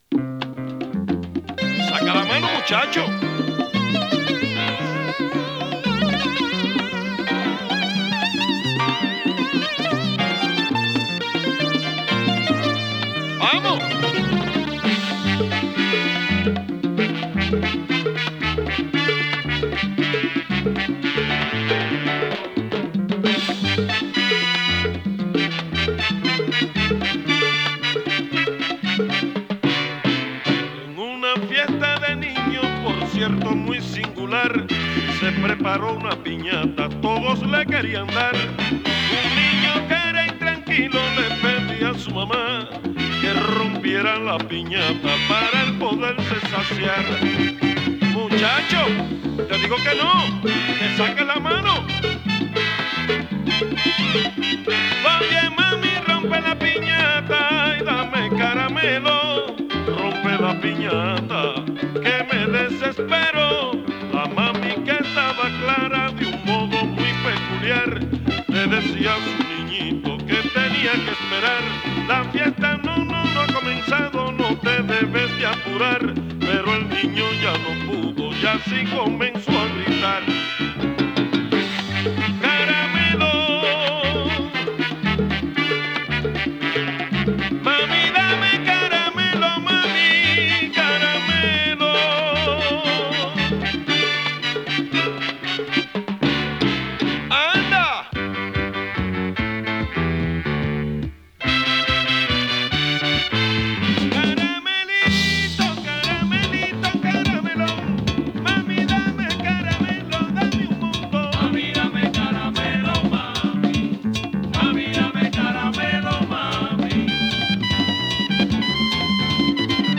様々な楽器のソロ回しやブレイクを用いて展開を変えて行く